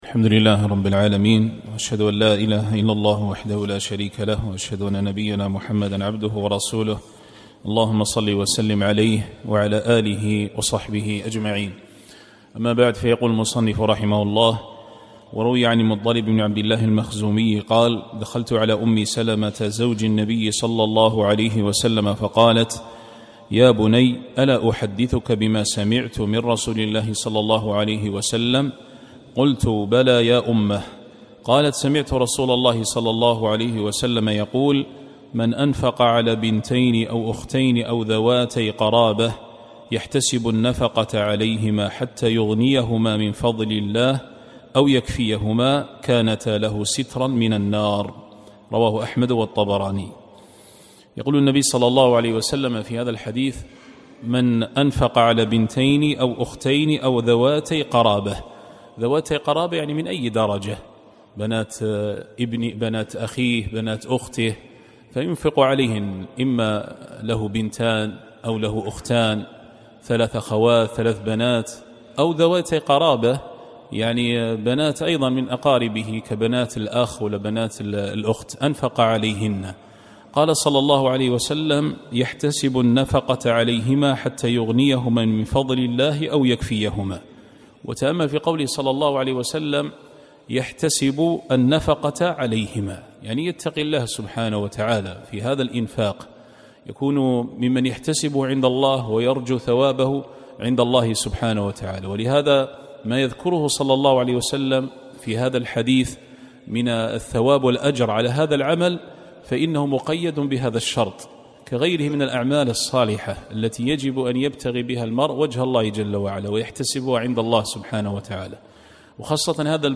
الدرس الثالث الثلاثون-33-